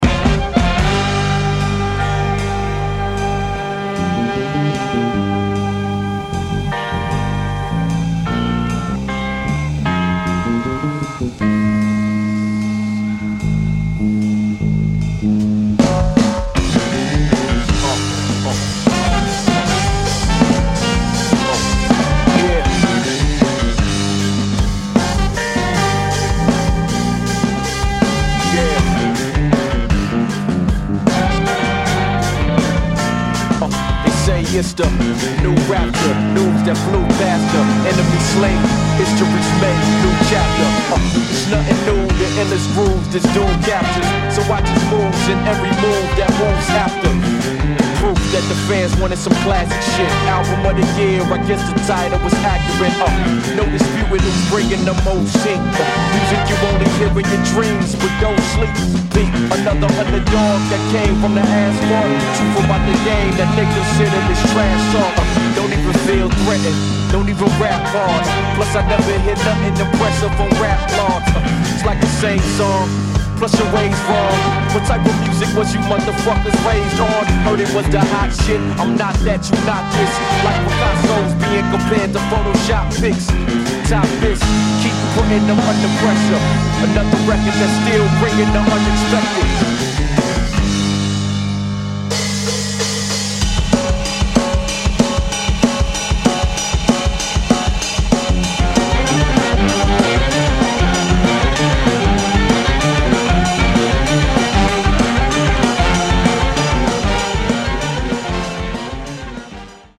raucous display of Funk-Rock vs. Hip-Hop
Hip Hop. Beats